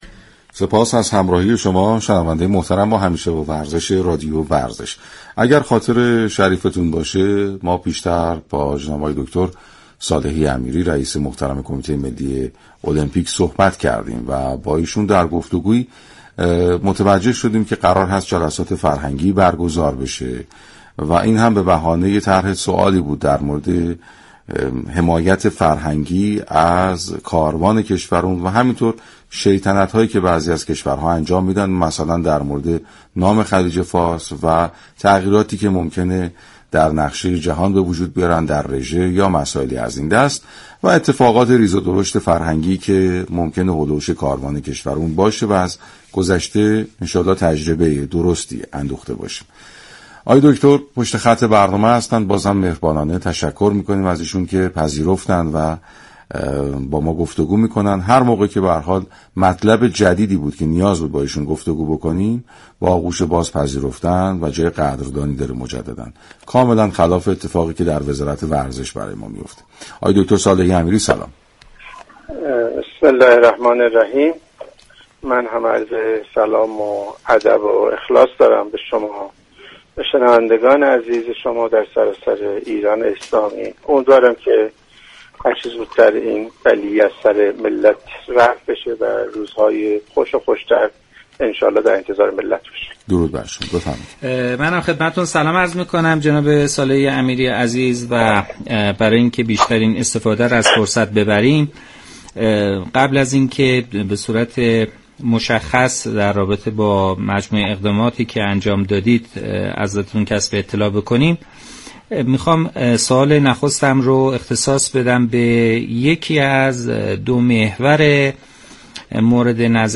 برنامه «همیشه با ورزش» رادیو ورزش دوشنبه 1 اردیبهشت با حضور سید رضا صالحی امیری، رئیس كمیته ملی المپیك به توضیح درباره مسائل فرهنگی كاروان المپیك پرداخت.